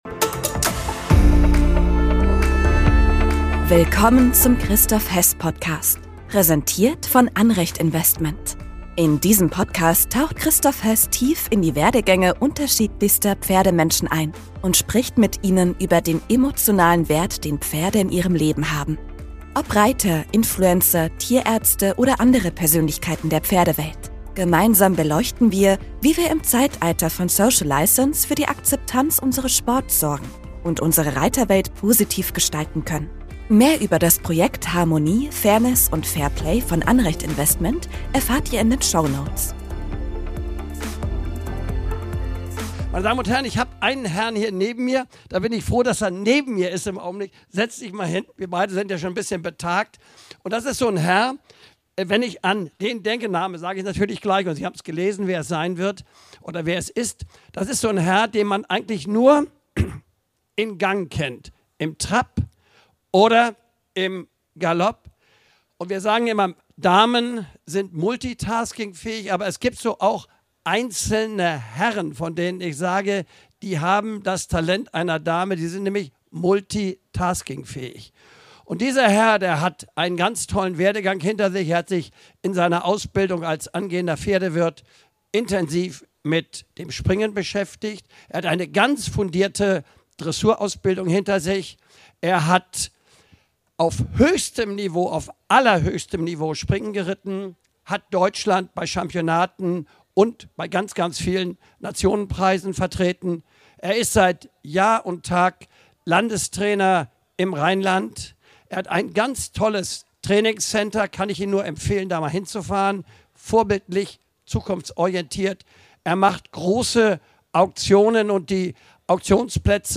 Diese Folge wurde als Live-Podcast bei den Bundeschampionaten in Warendorf im September 2024 aufgezeichnet.